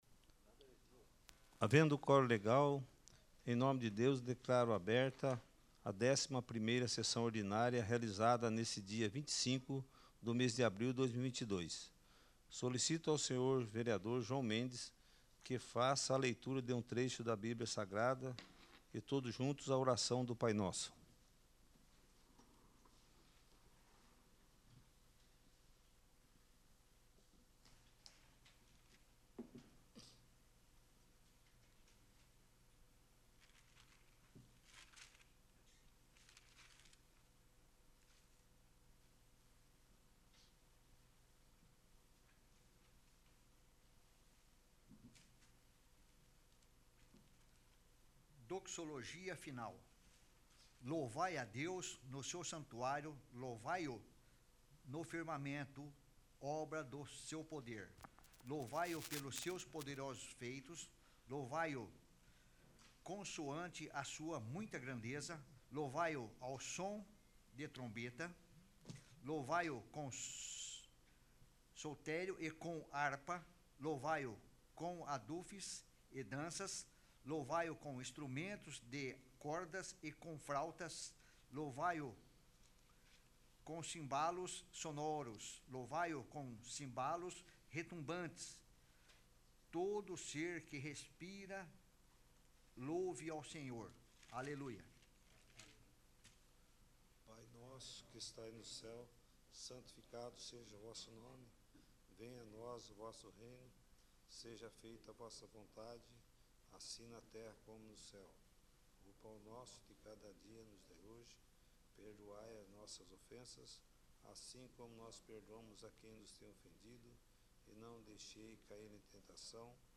11º. Sessão Ordinária